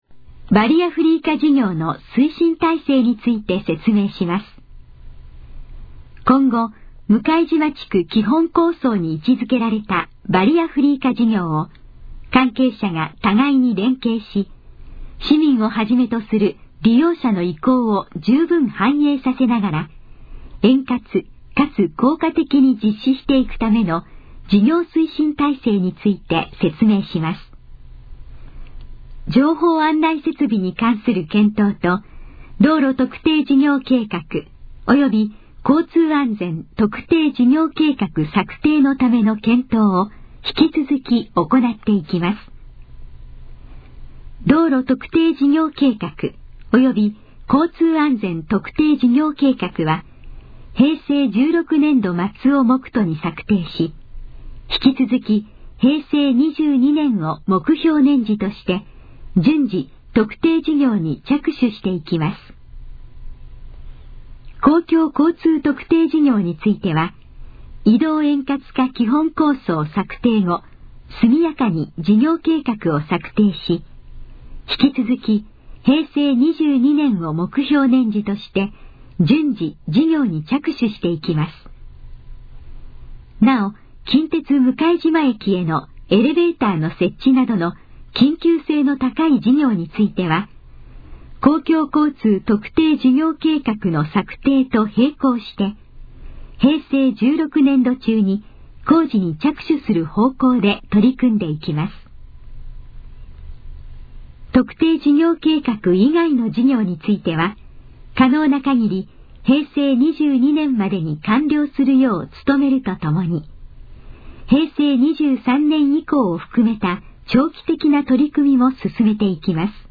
このページの要約を音声で読み上げます。
ナレーション再生 約349KB